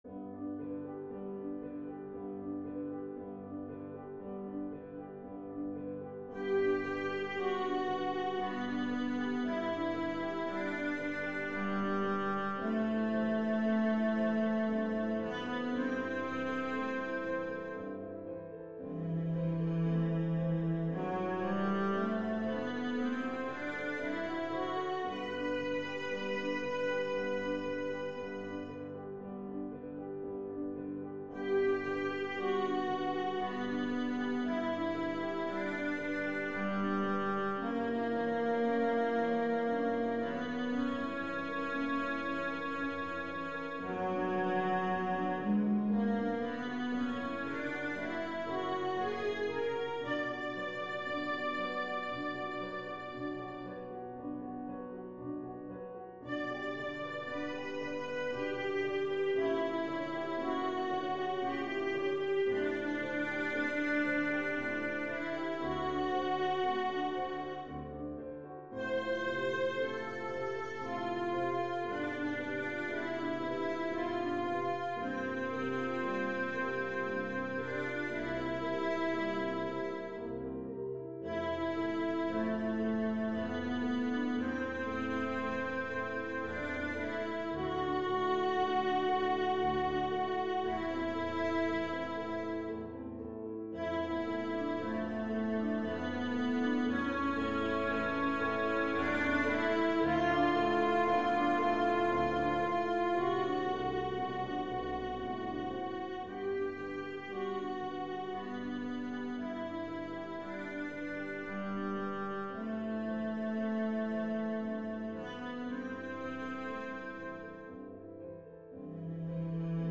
チェロ・アンサンブルのオススメ：
・白鳥（動物の謝肉祭より）：晴れた空に青い水面、光を浴びて白さ際立つ光景が目に浮かぶ優雅な１曲